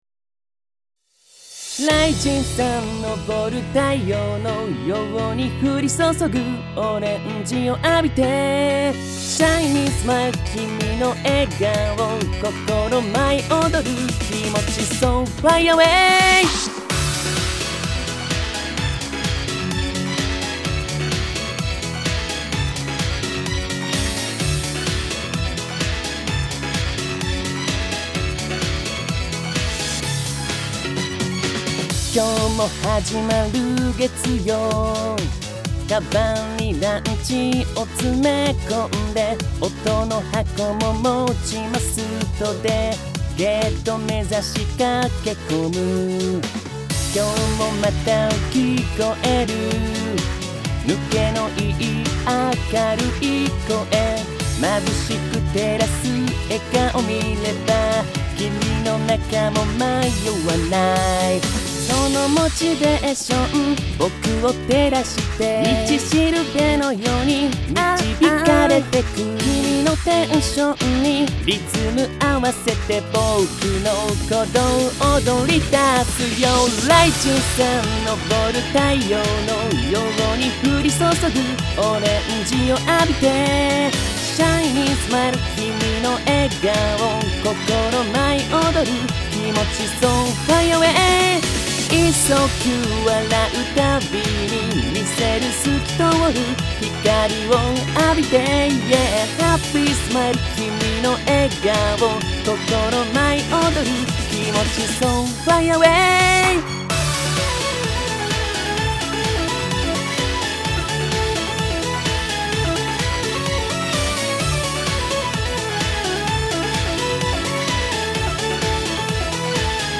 ボイスサンプル
セリフ
ナレーション
歌唱